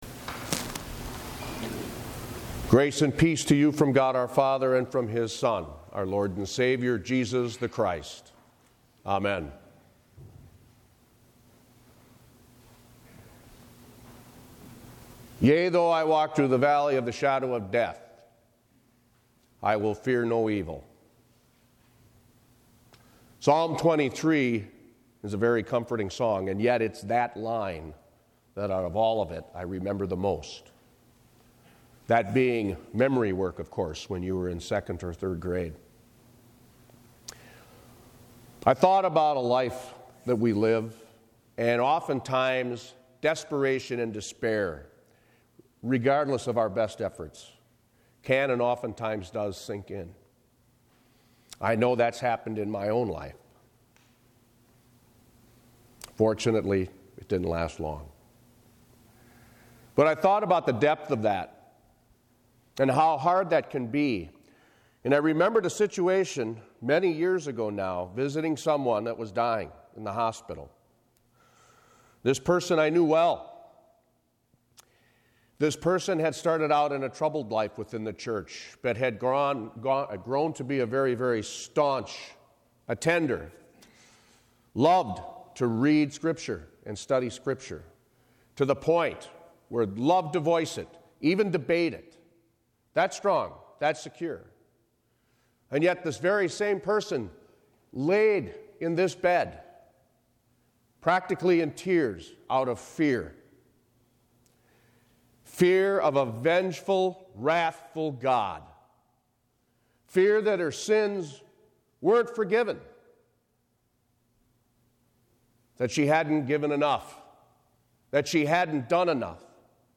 Another Sermon | Shepherd of the Lakes Lutheran Church | Sayner, Wisconsin